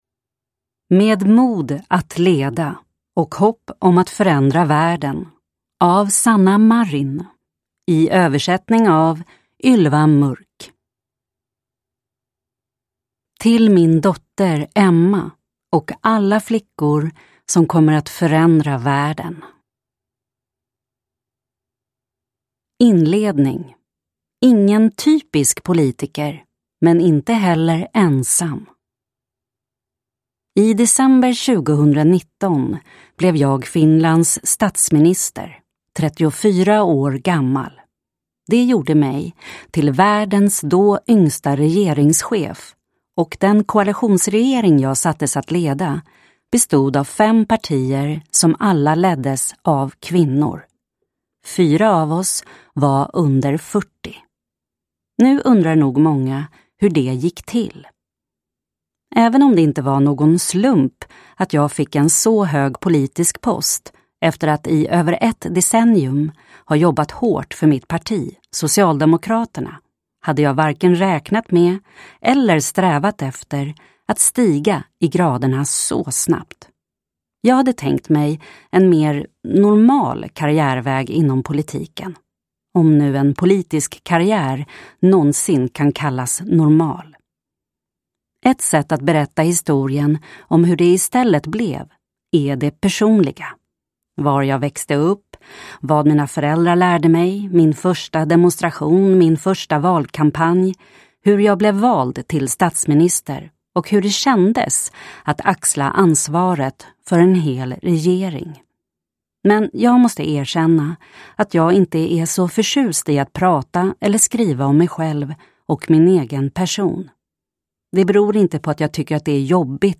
Med mod att leda : en biografi (ljudbok) av Sanna Marin